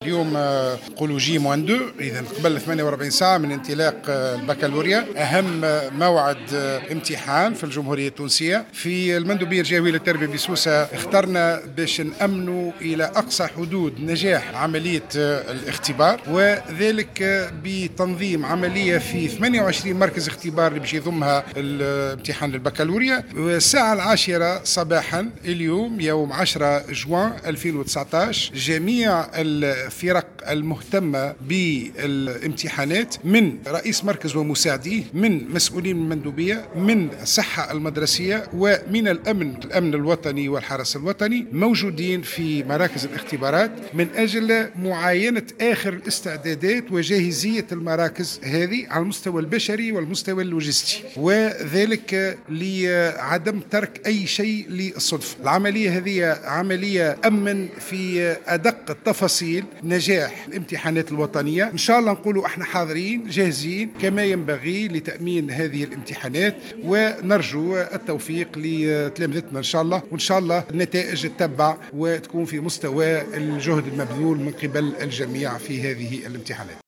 وقال المندوب الجهوي للتربية بسوسة نجيب الزبيدي في تصريح للجوهرة أف أم إنه تم اليوم إجراء عملية بيضاء حول إستلام العُلب المتضمنة للإختبارات وتخزينها في الفضاءات المخصصة لذلك في معهدي سهلول 4 والزاوية والقصيبة والثريات .كما أكد الزبيدي جاهزية كل الأطراف المتدخلة من رؤساء المراكز والمساعدين إضافة إلى المصالح الأمنية وممثلي وزارة الصحة لتأمين إجراء إمتحان البكالوريا في أحسن الظروف .